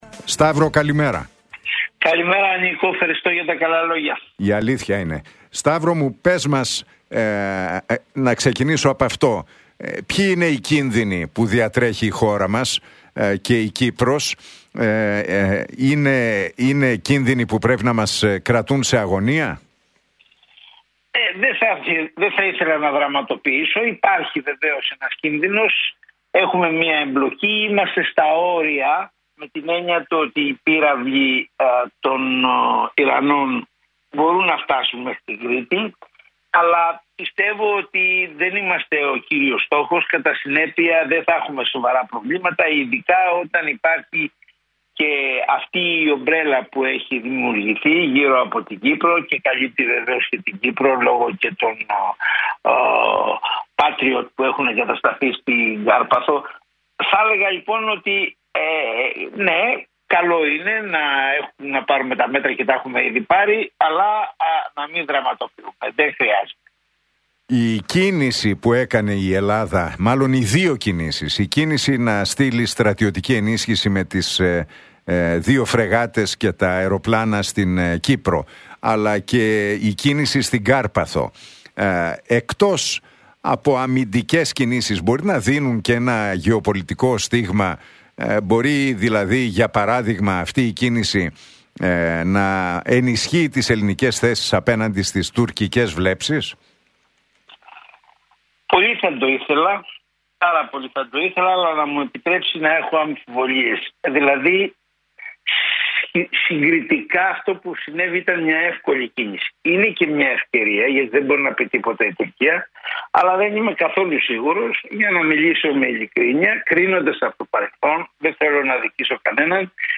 Για το εάν υπάρχει κίνδυνος για την Ελλάδα και την Κύπρο από τη γενικότερα έκρυθμη κατάσταση στη Μέση Ανατολή, τη στάση της Τουρκίας και την επόμενη ημέρα μίλησε ο δημοσιογράφος, Σταύρος Λυγερός στον Realfm 97,8 και τον Νίκο Χατζηνικολάου.